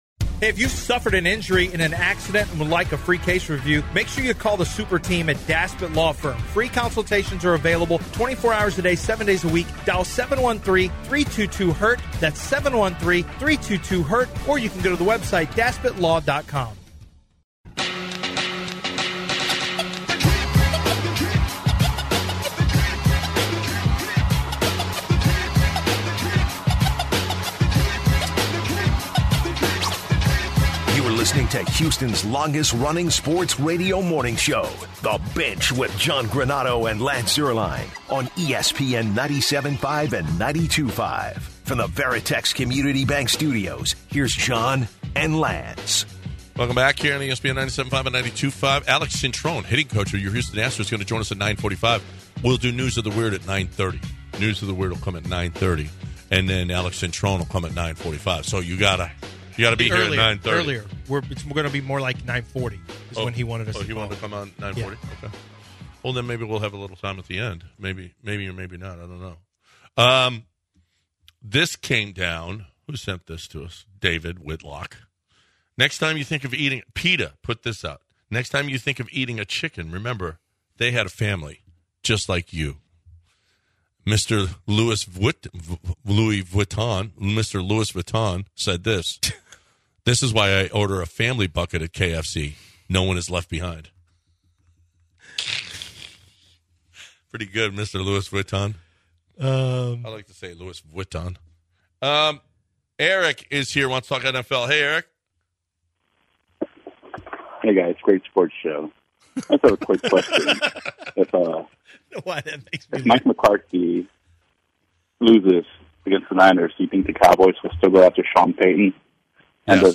The duo then indulges in a segment of "News of the Weird" before being joined by Astros Hitting Coach Alex Cintron to ask about...